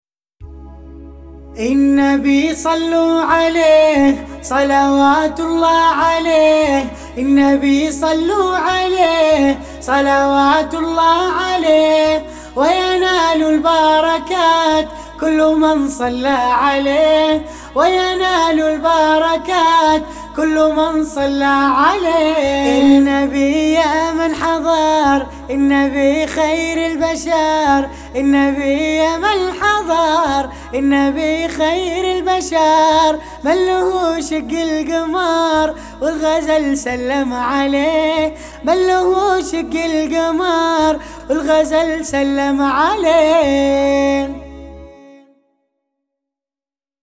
بيات